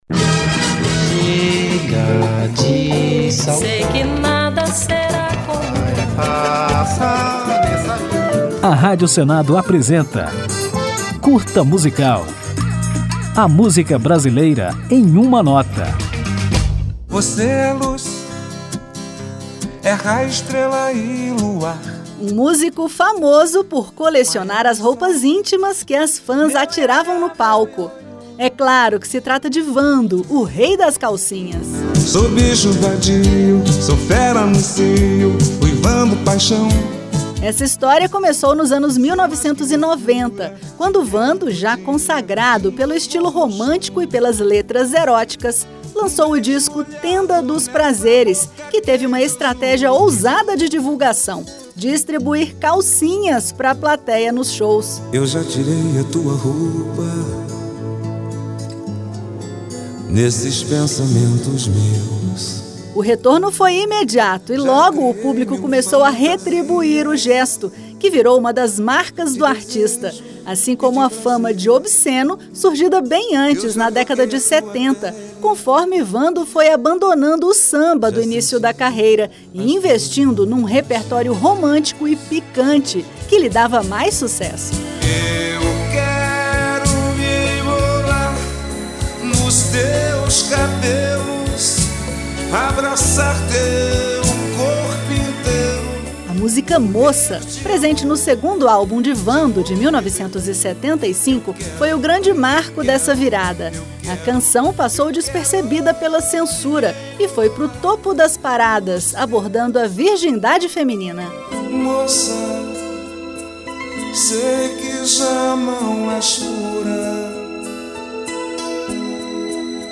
Aperte o play para conferir mais detalhes da história de Wando e ouvi-lo na música Fogo e Paixão.